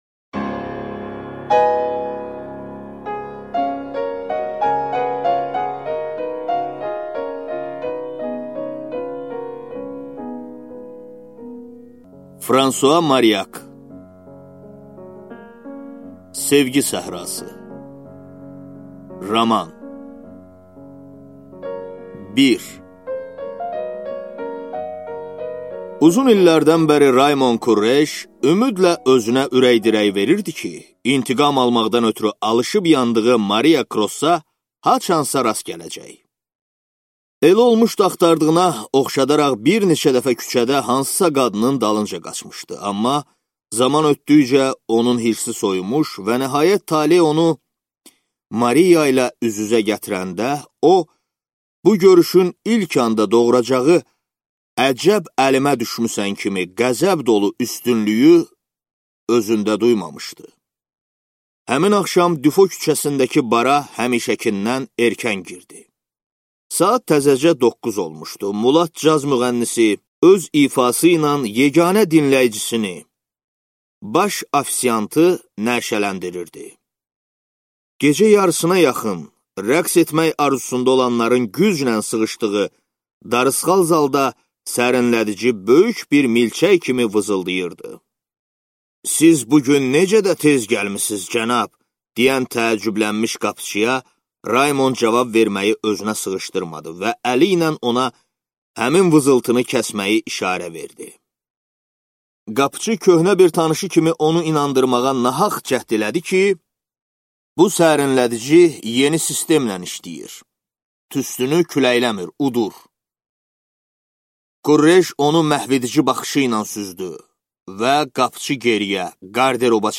Аудиокнига Sevgi səhrası | Библиотека аудиокниг
Прослушать и бесплатно скачать фрагмент аудиокниги